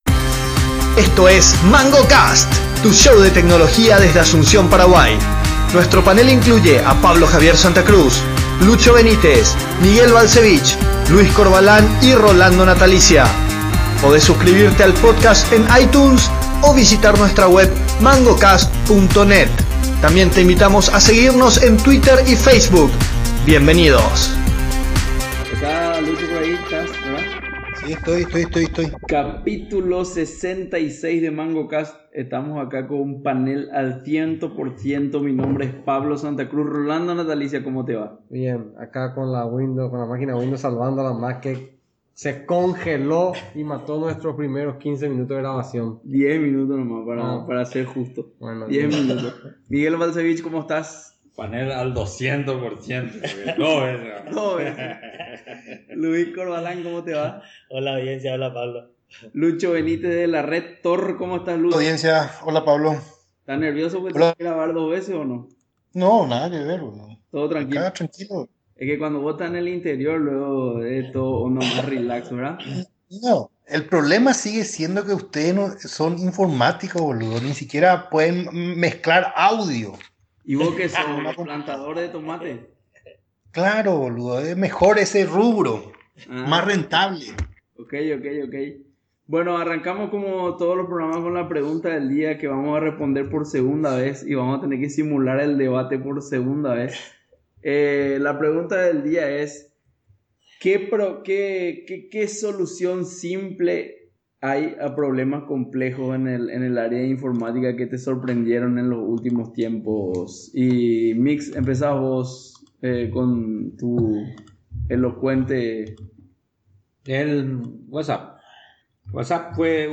Volvimos a repetir la grabación, esta vez con Windows y un programa llamado iFree Skype Recorder. Graba cada pata de la llamada en un canal, y es más que suficiente para nuestro propósito, sobretodo porque se el audio se preprocesa antes de que sea levantado para su consumo. Pero su funcionamiento depende de Skype y por supuesto, Skype falló, no una sino varias veces, entre otras cosas porque al menos una vez falló de Internet.